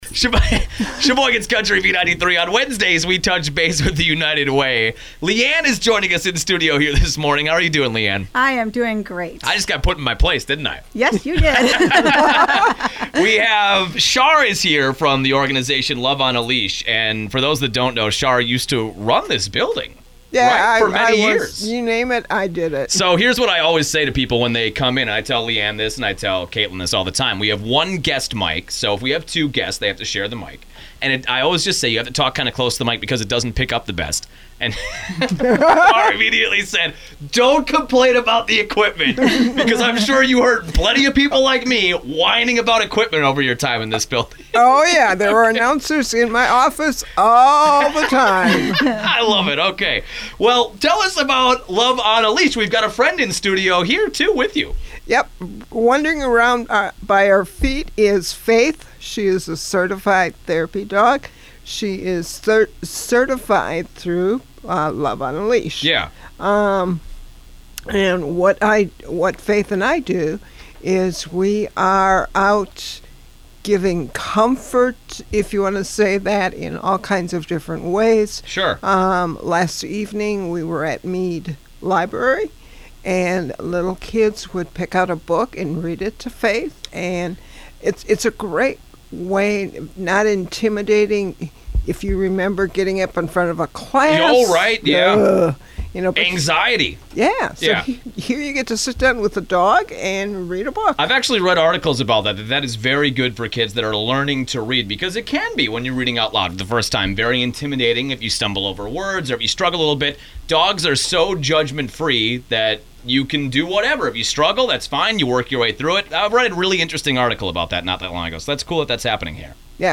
LOVE ON A LEASH- Radio Spot
This week on the radio: LOVE ON A LEASH.
Thank you to Midwest Communications for sponsoring the weekly radio spot on WHBL and B93 Sheboygan’s Country Radio Station!